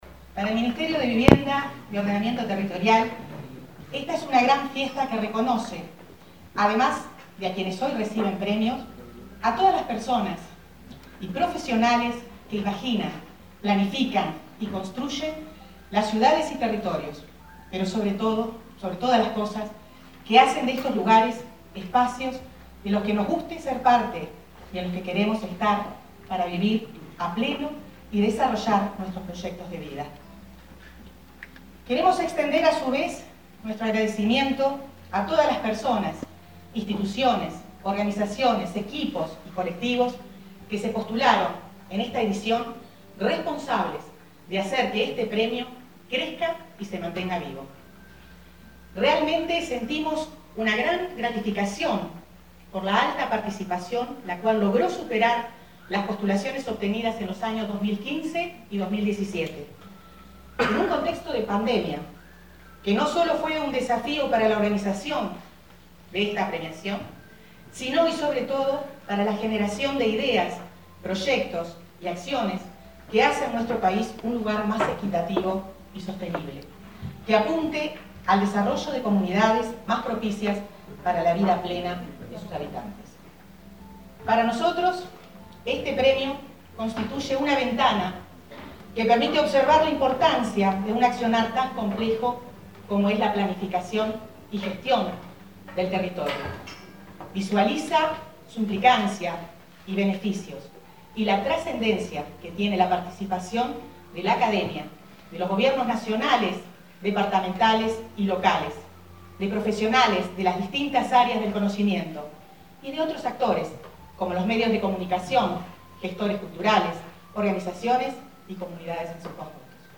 Palabras de la ministra de Vivienda, Irene Moreira
Palabras de la ministra de Vivienda, Irene Moreira 30/11/2021 Compartir Facebook X Copiar enlace WhatsApp LinkedIn La ministra de Vivienda, Irene Moreira, participó de la entrega del Premio Nacional de Ordenamiento Territorial y Urbanismo 2021, realizado este martes 30 en el auditorio del Sodre.